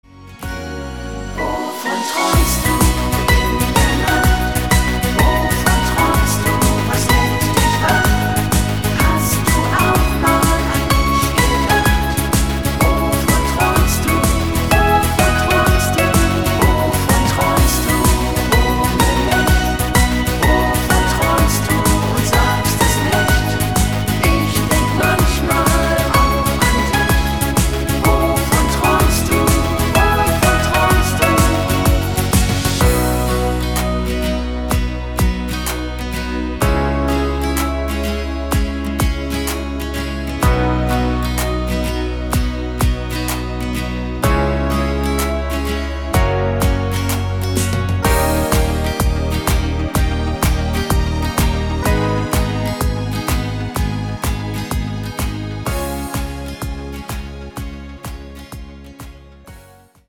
Rhythmus  Rock Shuffle